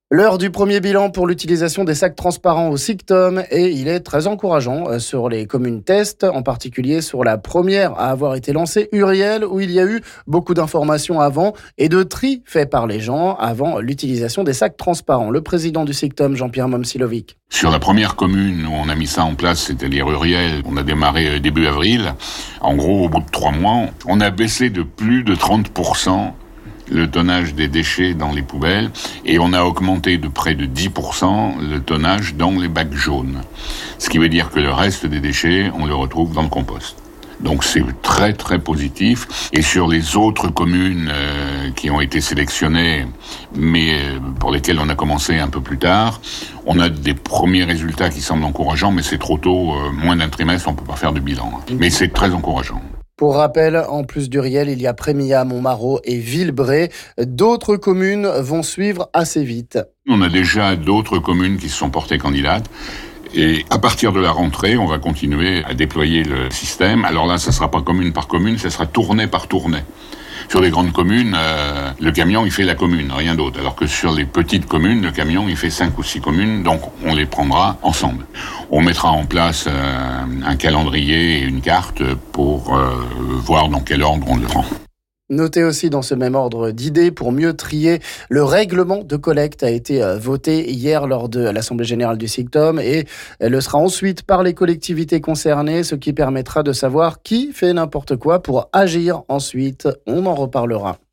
Le président du SICTOM Jean-Pierre Momcilovic nous en parle ici...